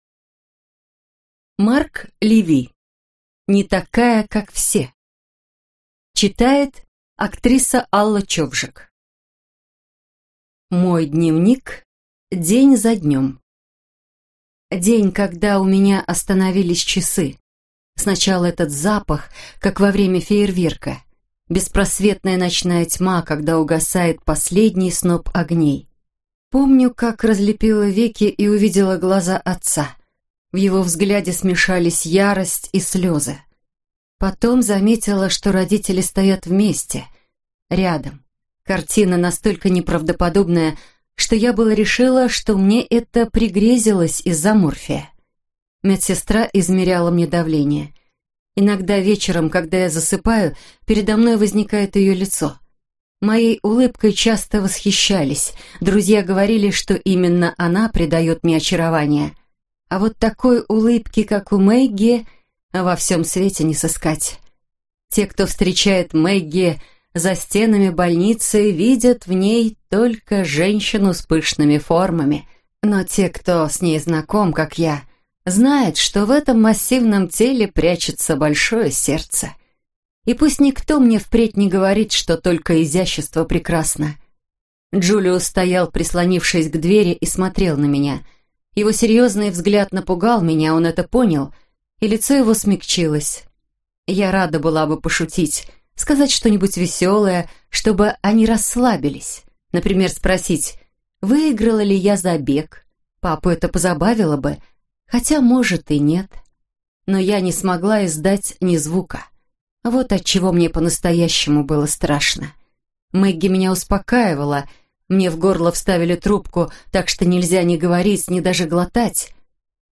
Аудиокнига Не такая, как все - купить, скачать и слушать онлайн | КнигоПоиск